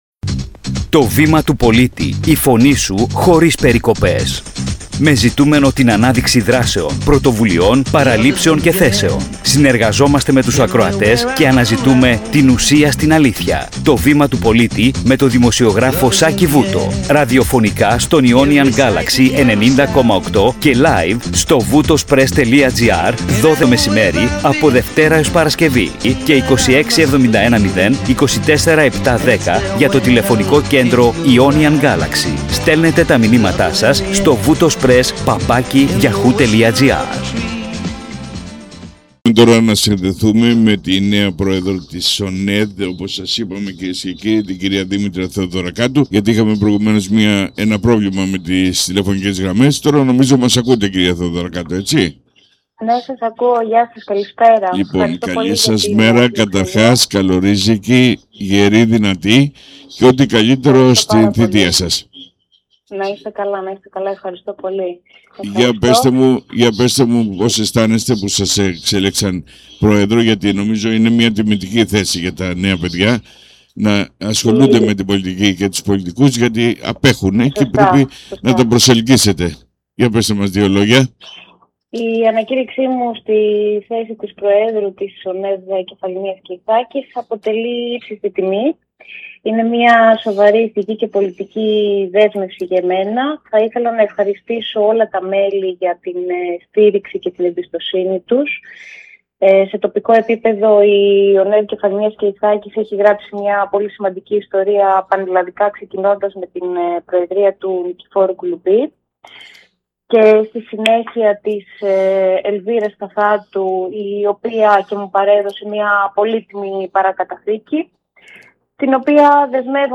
Είχαμε προηγουμένως ένα μικρό πρόβλημα με τις τηλεφωνικές γραμμές, αλλά τώρα νομίζω μας ακούτε.